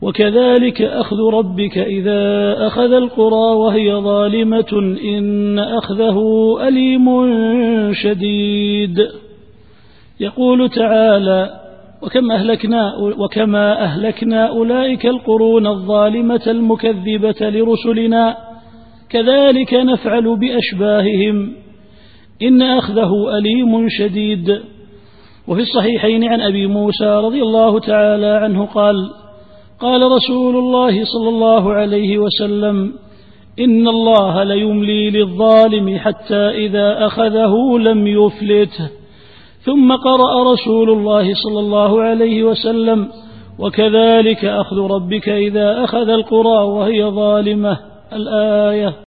التفسير الصوتي [هود / 102]